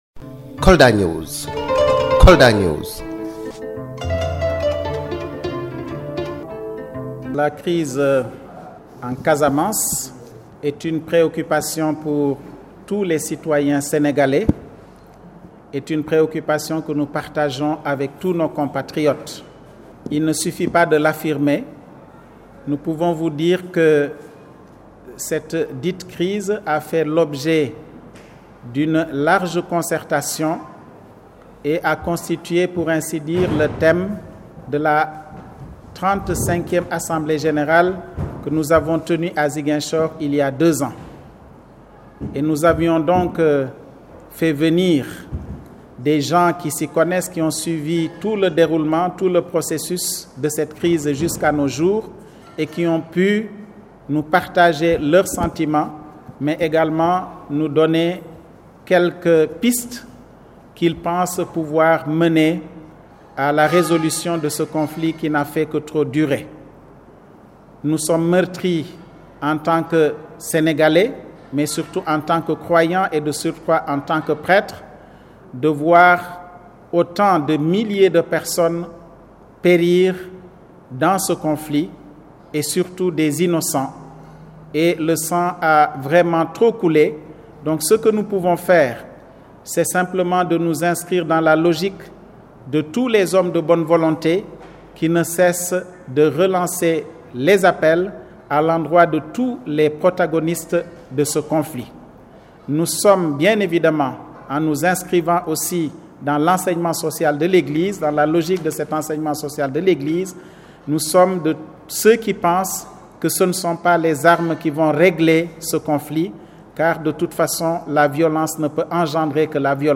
Convaincue du fait que ce ne sont pas les armes qui vont régler la crise en Casamance, l’Union du Clergé Sénégalais invite les protagonistes à la table de négociation.  Cet appel à la paix a été réitéré à l’occasion de la 37e assemblée générale de l’UCS qui vient de prendre fin ce weekend dans la capitale du Fouladou.